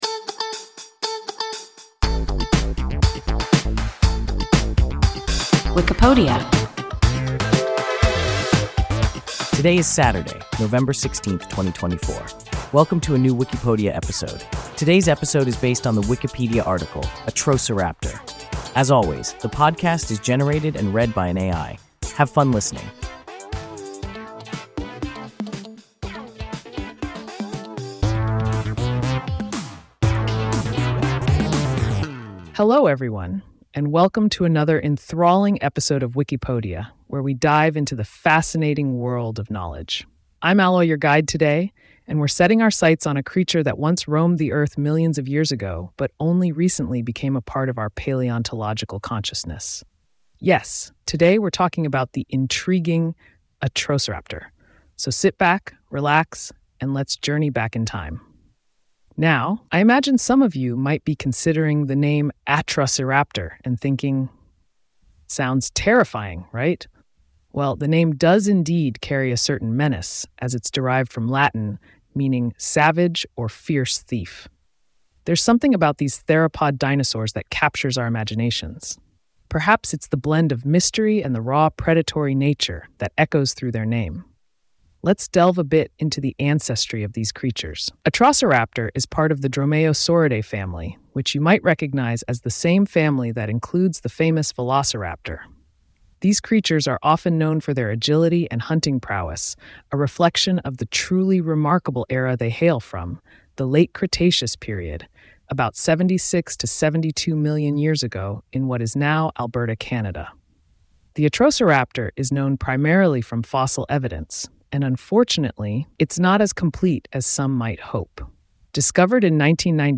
Atrociraptor – WIKIPODIA – ein KI Podcast